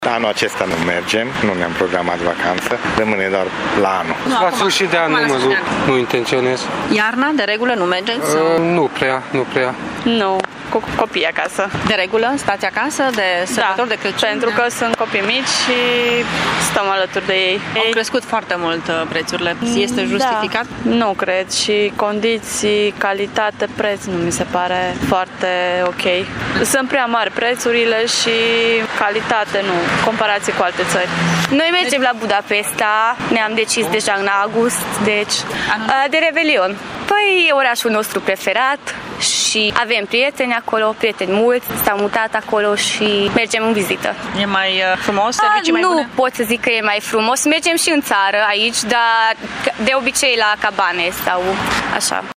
Nu toată lumea își permite să meargă în vacanță de sărbători. Mulți târgumureșeni lucrează sau petrec acasă cu familia sau prietenii: